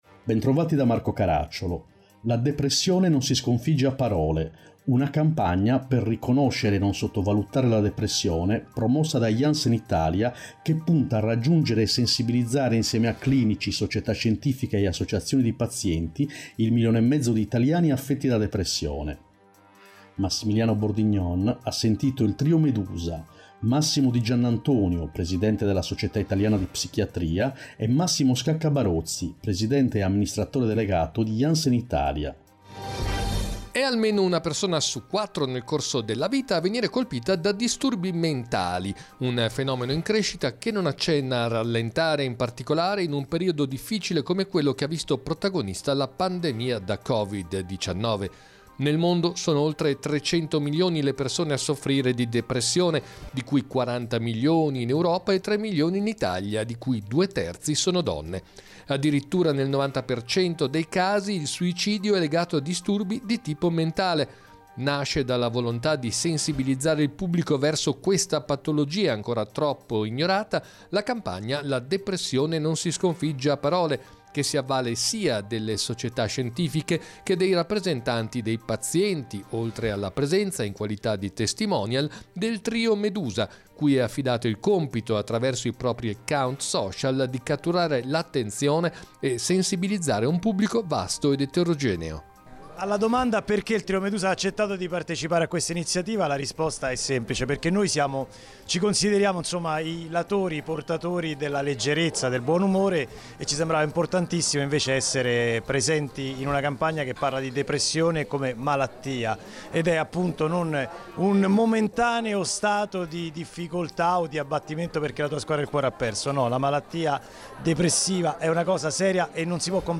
Puntata con sigla